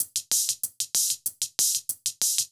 Index of /musicradar/ultimate-hihat-samples/95bpm
UHH_ElectroHatD_95-04.wav